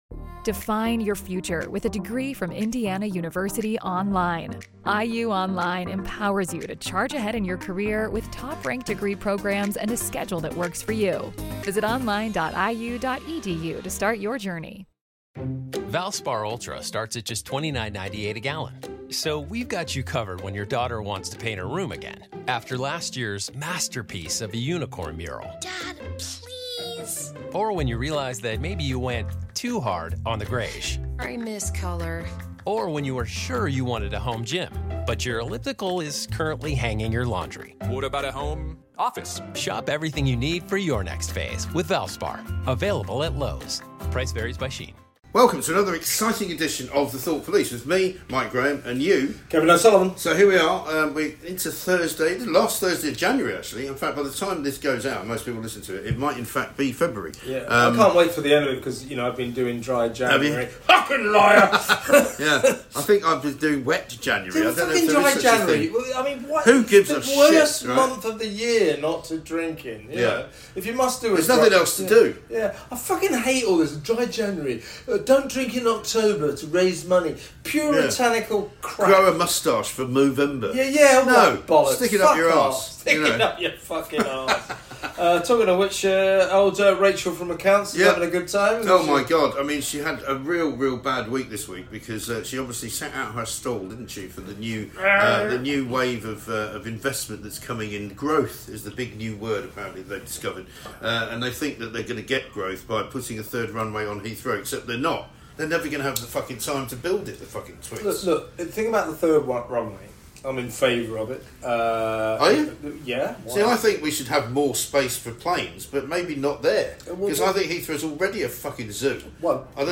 The lads are back with another rage filled deep dive into the past weeks top stories, and the very worst...